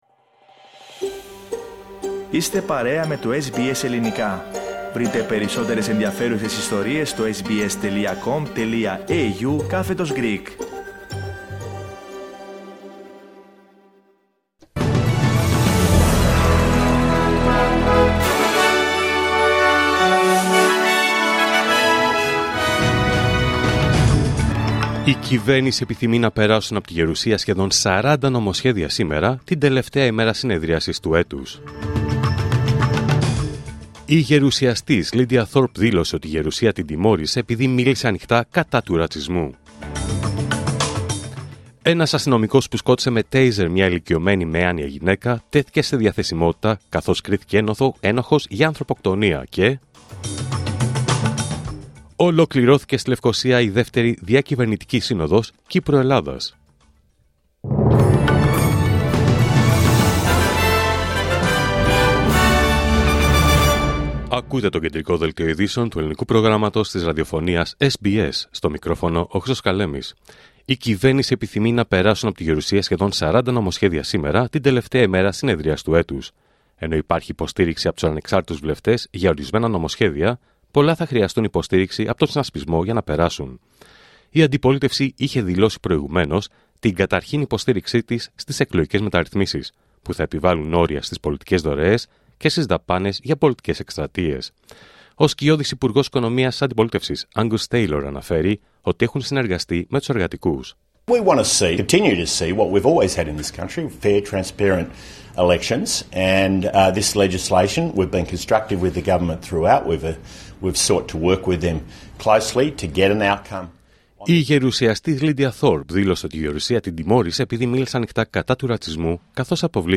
Δελτίο Ειδήσεων Πέμπτη 28 Νοέμβριου 2024